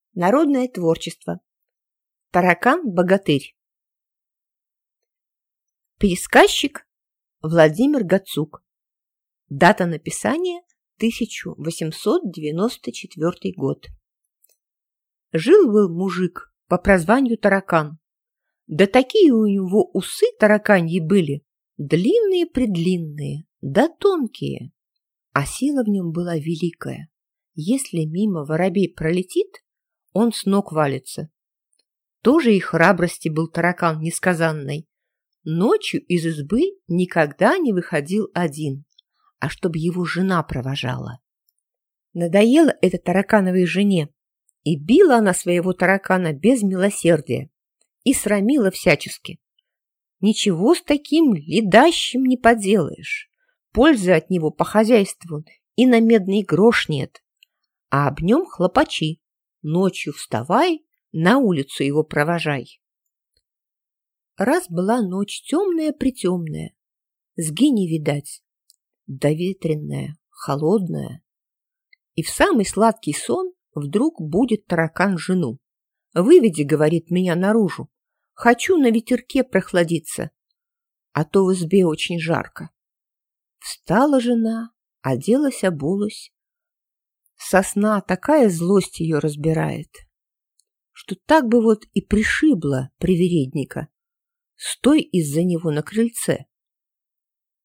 Аудиокнига Таракан-богатырь | Библиотека аудиокниг